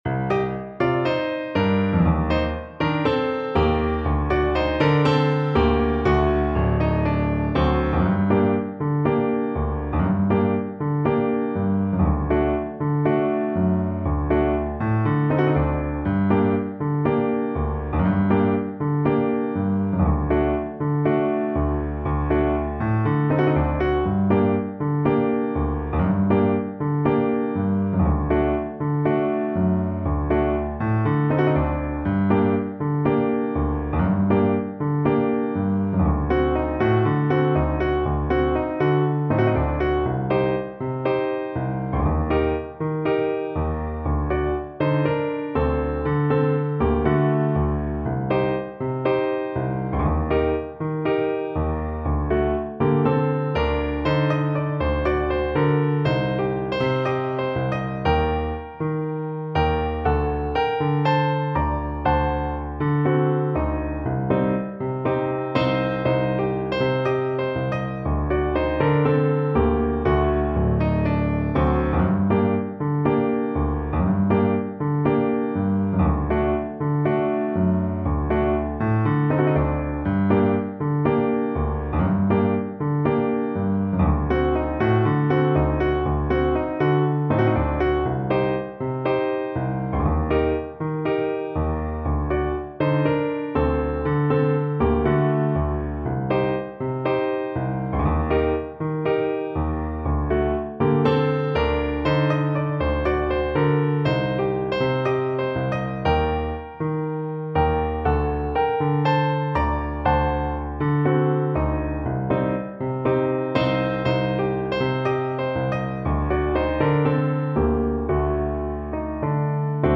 Trombone version
Trombone
Traditional Music of unknown author.
2/2 (View more 2/2 Music)
Bb major (Sounding Pitch) (View more Bb major Music for Trombone )
Slow two in a bar =c.60